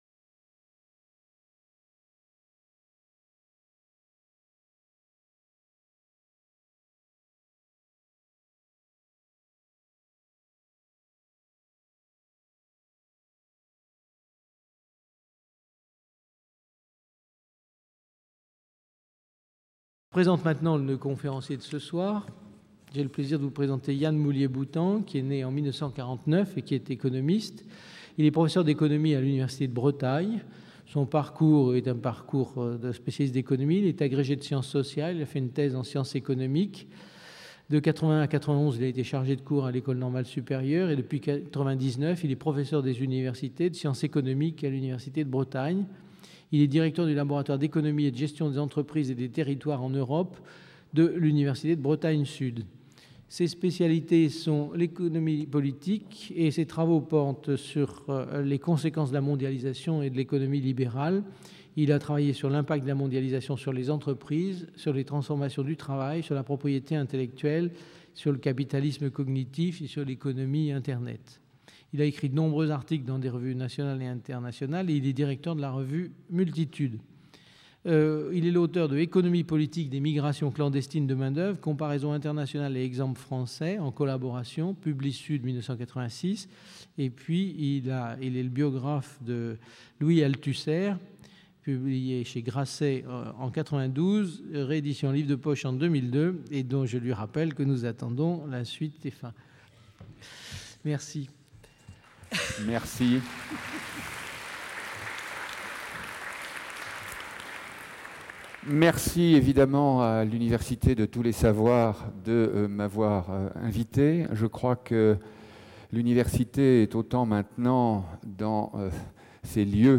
À l'âge d'une transformation profonde des concepts politiques de la souveraineté, de la représentation que d'aucuns nomment « impériale », la présente conférence se propose d'explorer un autre chemin.